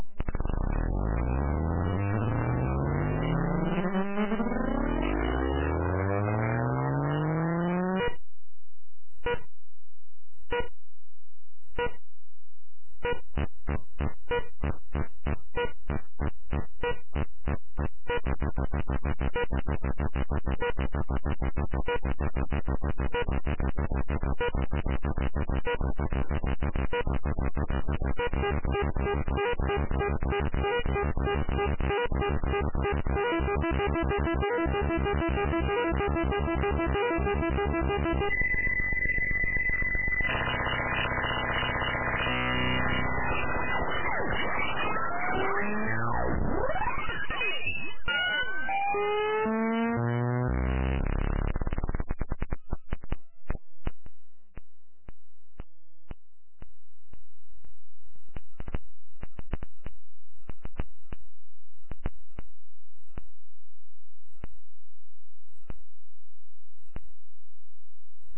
a 1 minute long "electronic" ""music"" track